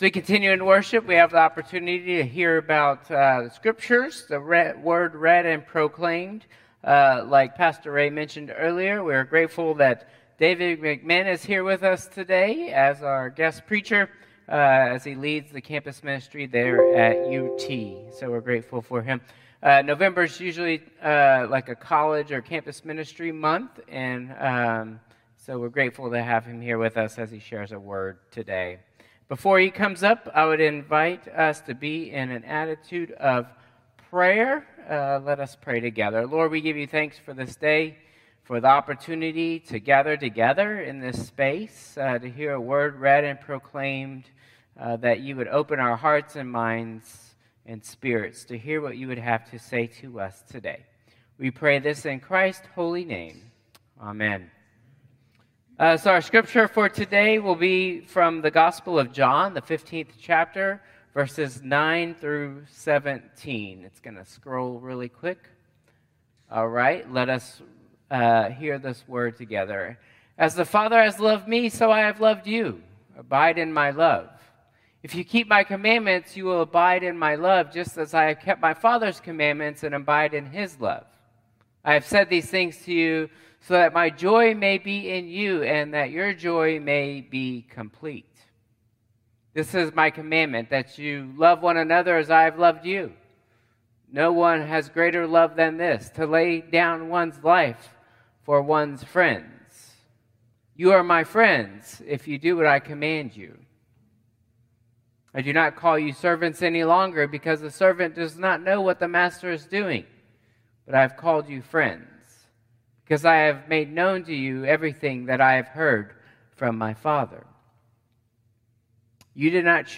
11/10/2024 Traditional Service